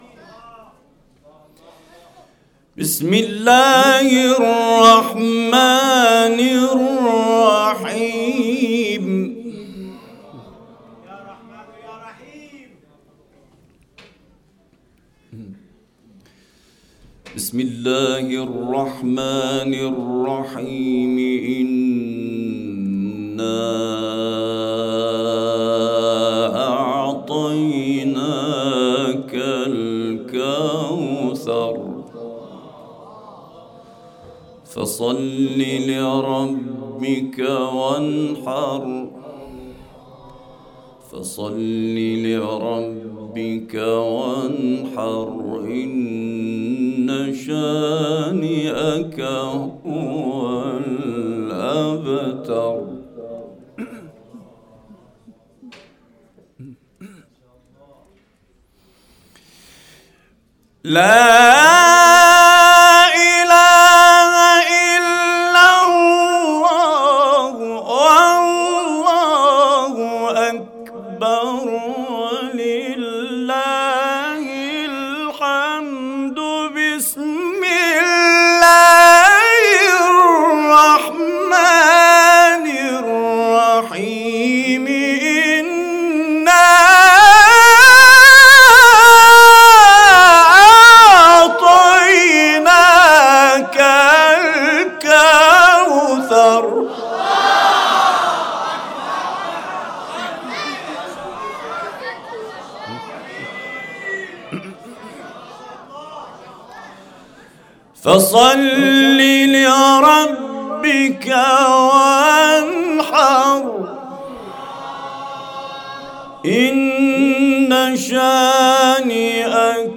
القارئ سعيد طوسي - لحفظ الملف في مجلد خاص اضغط بالزر الأيمن هنا ثم اختر (حفظ الهدف باسم - Save Target As) واختر المكان المناسب